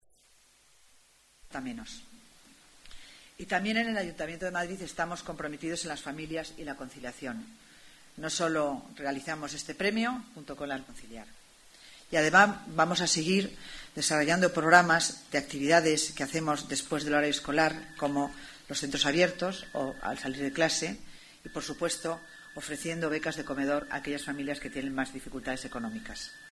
Nueva ventana:Declaraciones de la delegada de Familia y Asuntos Sociales, C. Dancausa